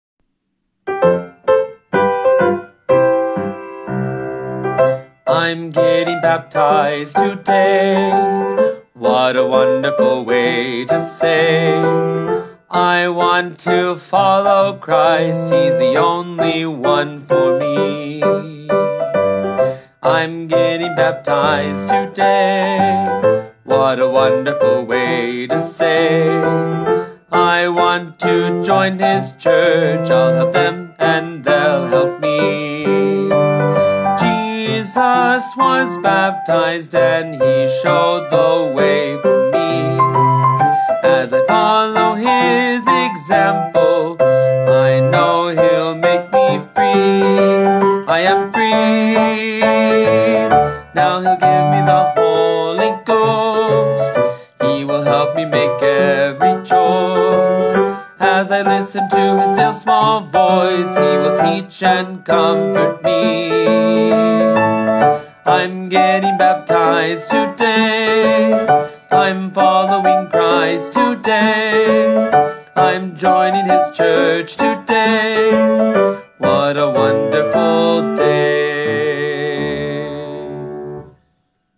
Voice and Piano
Hymn arrangement